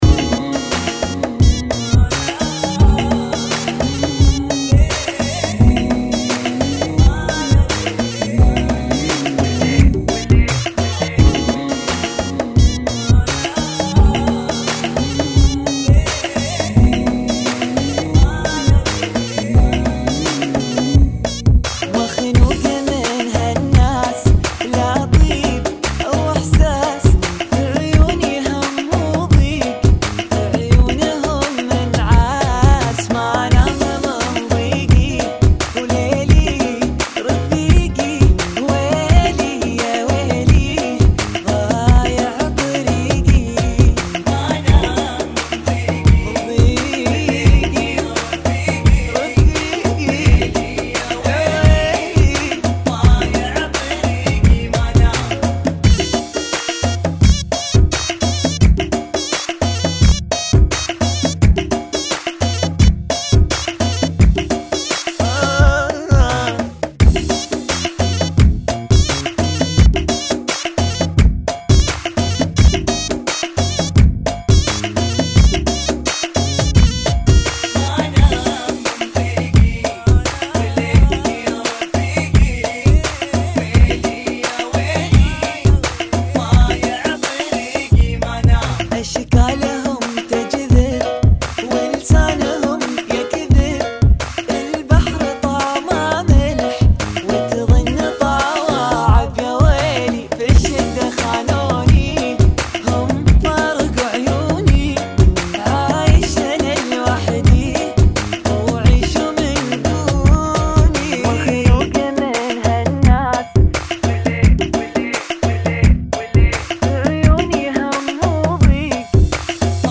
Funky [ 86 Bpm